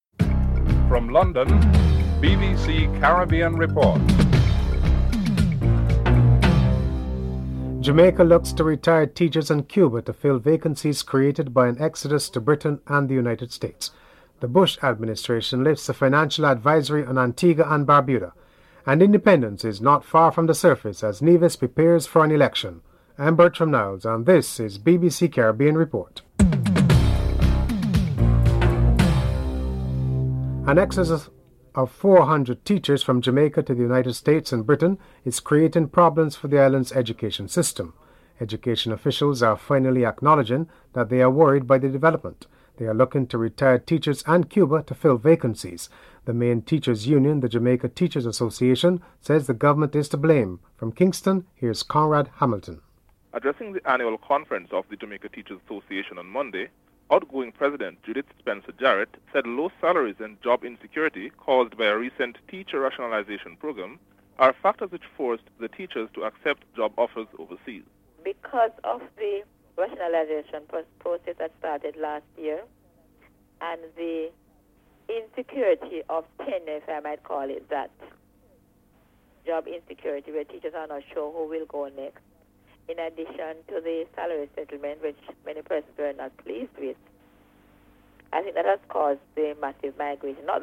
1. Headlines (00:00-00:29)
3. Bush administration lists a financial advisory on Antigua and Barbuda. Prime Minister Lester Bird is interviewed (02:50-05:49)